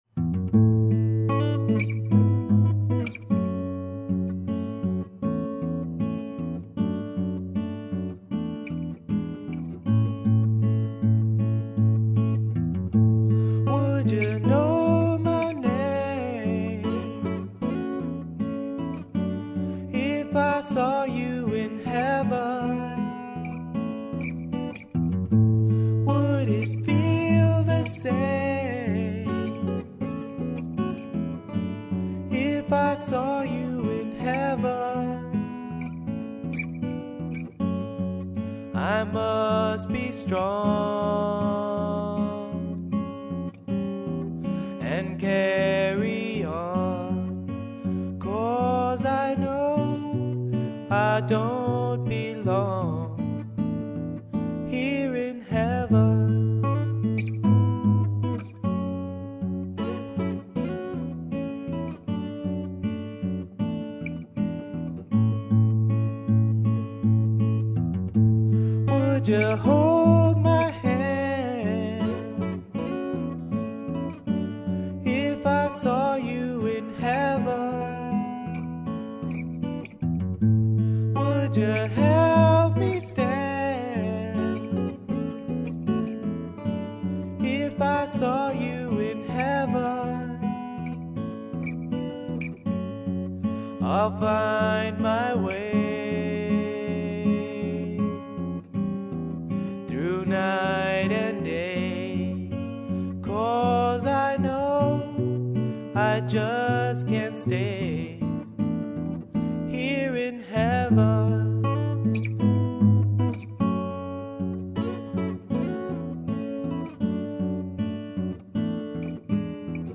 This is a cover